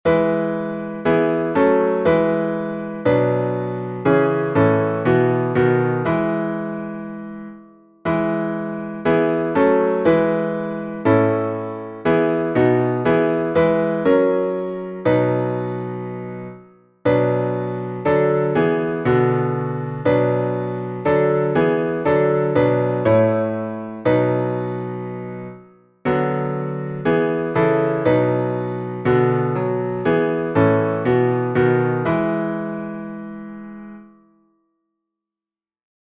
Modo: mixolídico
Downloads Áudio Áudio instrumental (MP3) Áudio instrumental (MIDI) Partitura Partitura 4 vozes (PDF) Cifra Cifra (PDF) Cifra editável (Chord Pro) Mais opções Página de downloads
salmo_116A_instrumental.mp3